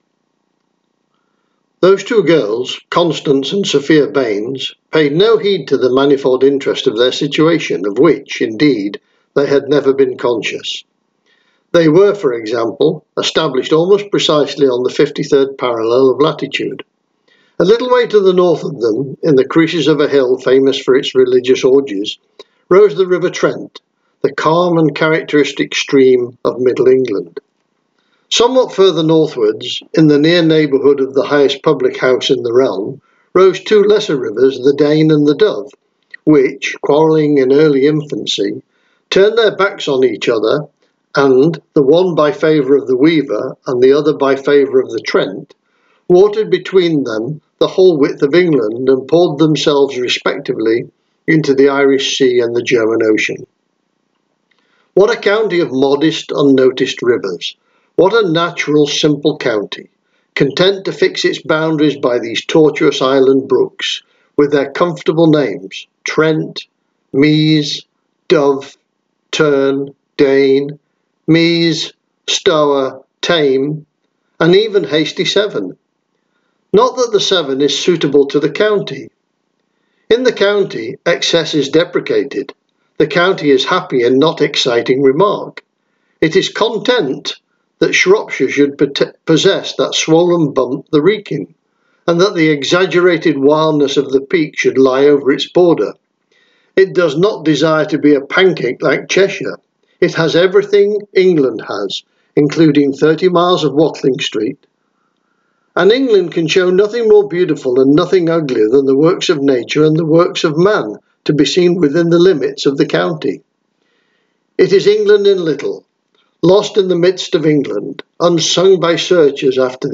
I don’t speak any dialect, but I clearly have an accent that, to many, sounds ‘northern’ because of the short vowel pronunciation characteristic of my speech. In this clip, I’m reading the first few paragraphs from Bennett’s The Old wives’ Tale: